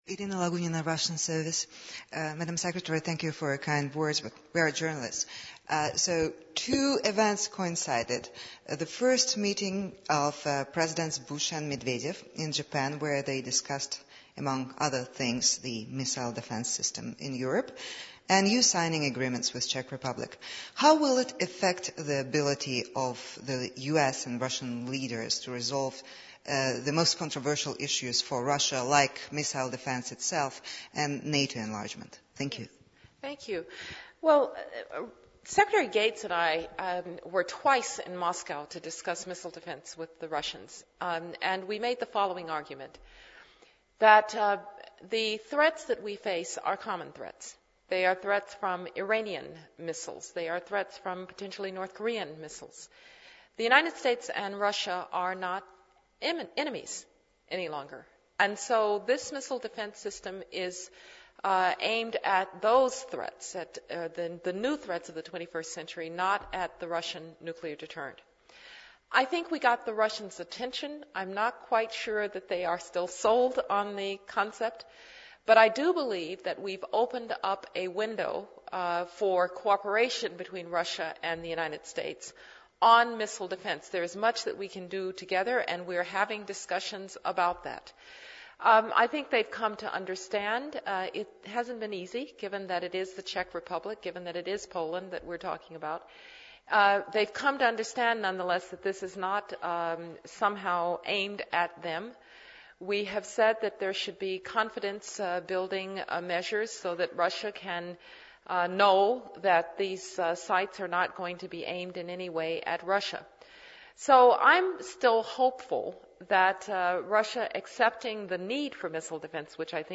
RiceQ&A.mp3